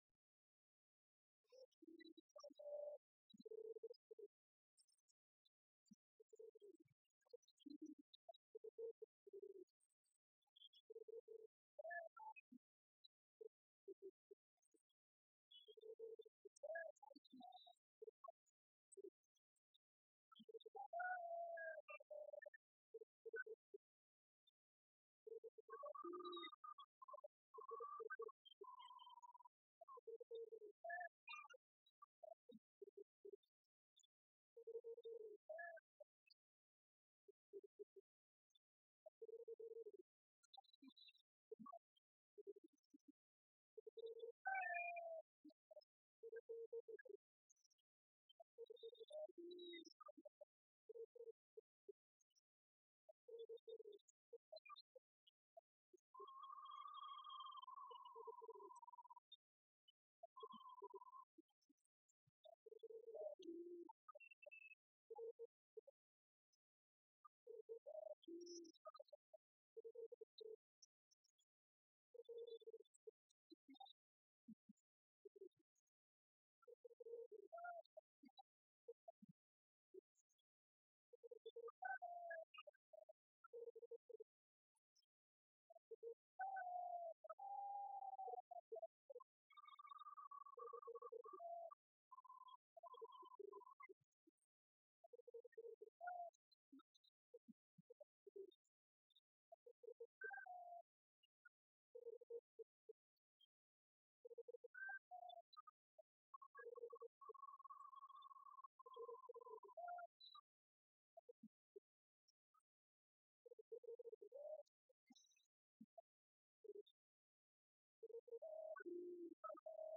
Folk songs, Tswana
Folk music
Field recordings
Africa South Africa Saulspoort f-sa
sound recording-musical
Hunting song.